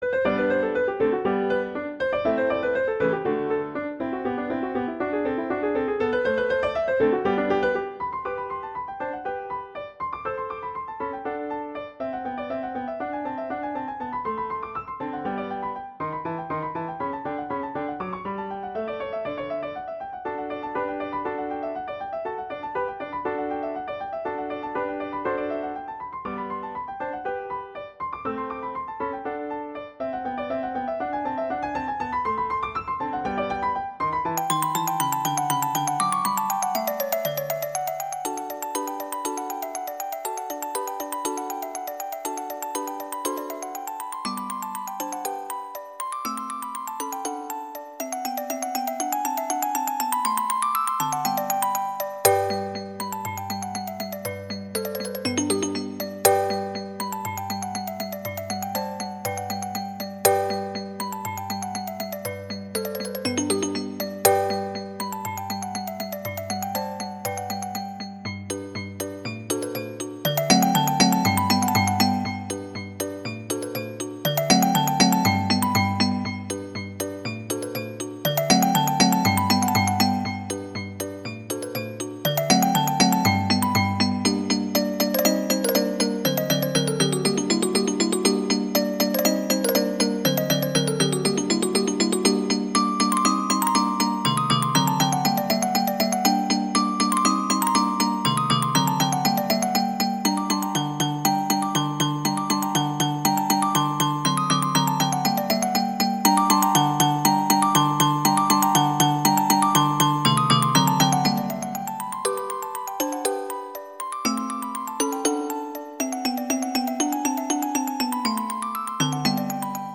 (originally for piano trio)
classical
♩=120 BPM